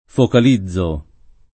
focalizzare v.; focalizzo [ fokal &zz o ]